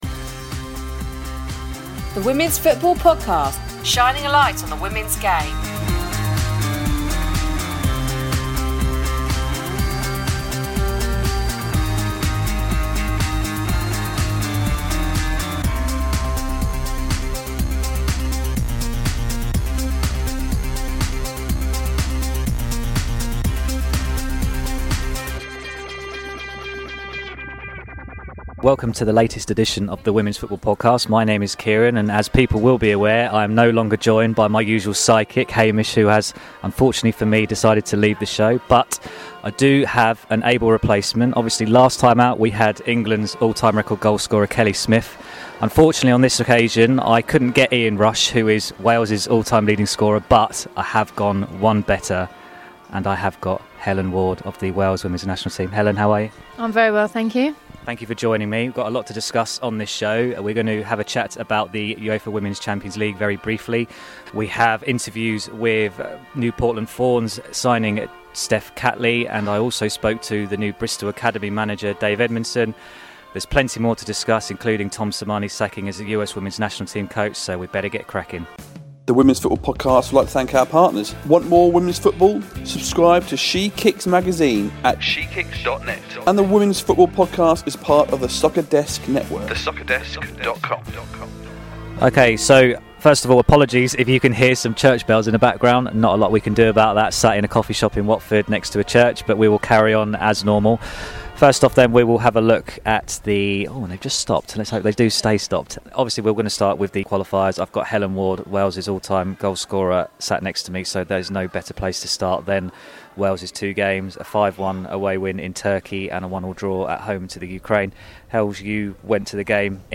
If that wasn’t enough, we review the opening weekend of the NWSL, and speaking of the American league, the show features an interview with Portland Thorns new signing and Australia international, Steph Catley.